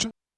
Drums_K4(37).wav